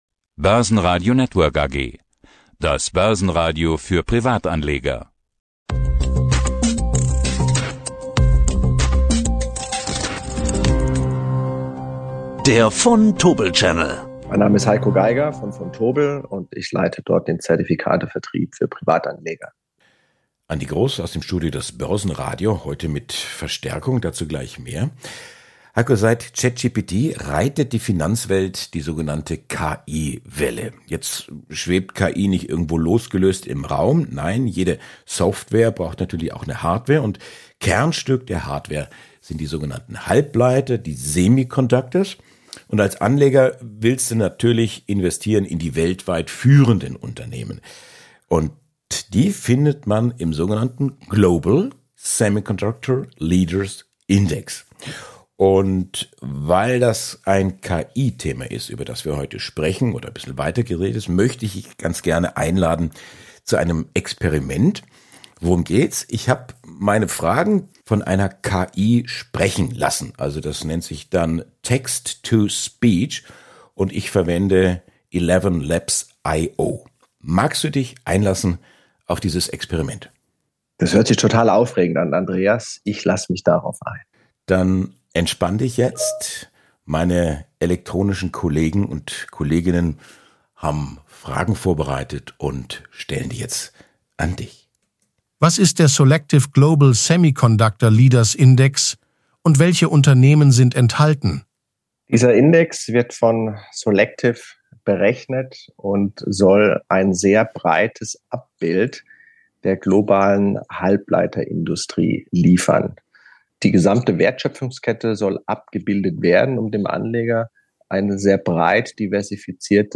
Das Interview wurde mit Unterstützung von KI geführt.